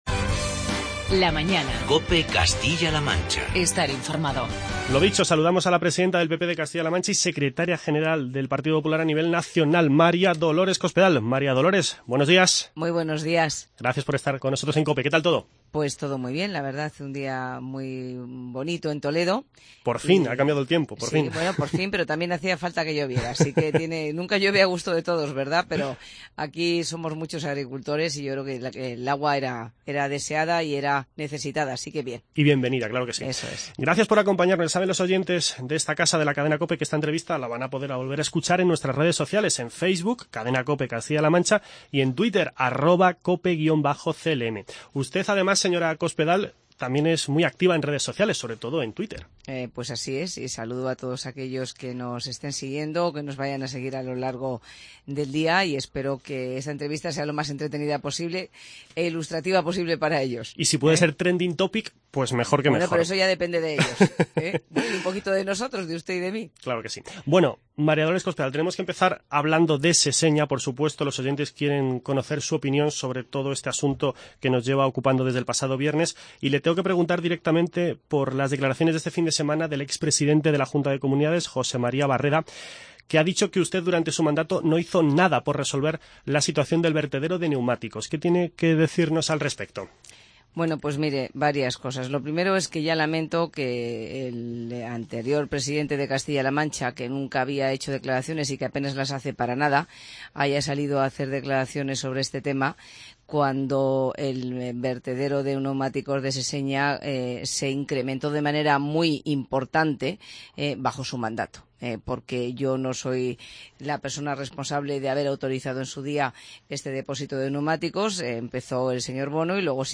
Escuche la entrevista con la presidenta del PP de Castilla-La Mancha y secretaria general del Partido Popular a nivel nacional, María Dolores Cospedal. La dirigente popular se ha referido a la última hora del incendio de Seseña y a la coalición Unidos Podemos, entre otras cuestiones de interés.